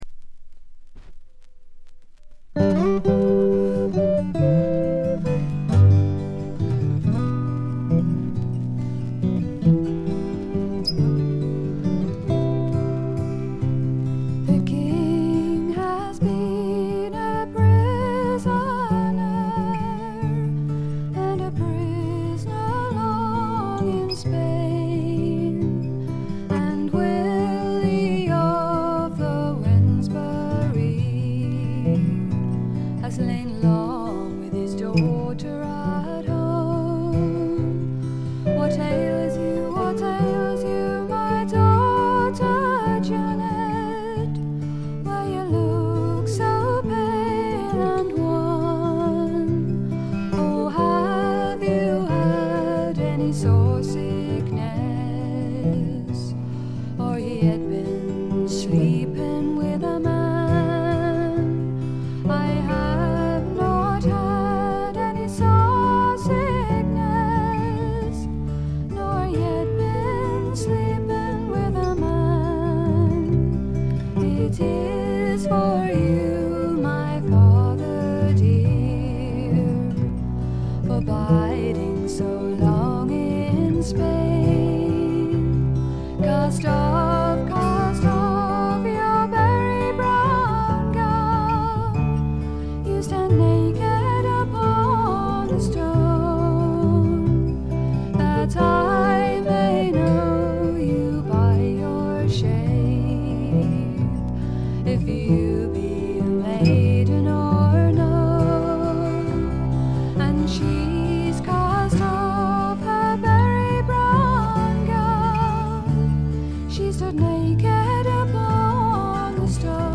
部分試聴ですが軽微なノイズ感のみ。
試聴曲は現品からの取り込み音源です。